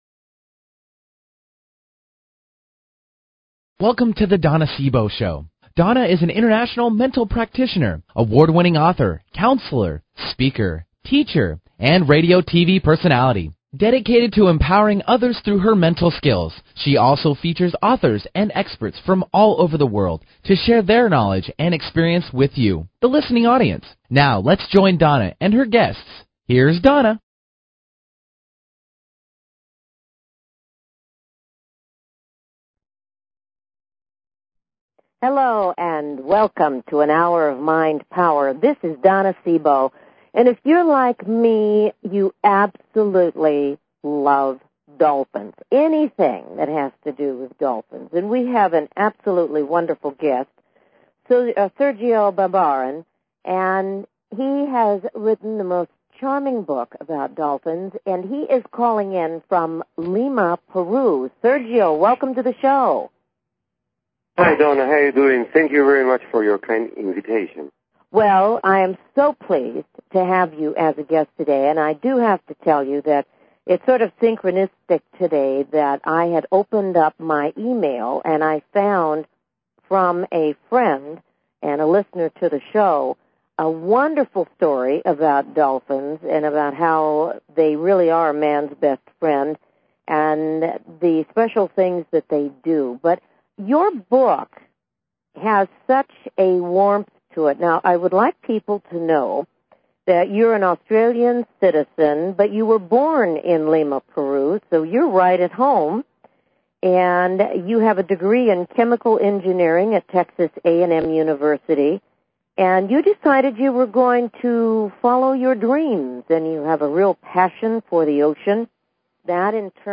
Her interviews embody a golden voice that shines with passion, purpose, sincerity and humor.
Talk Show
Callers are welcome to call in for a live on air psychic reading during the second half hour of each show.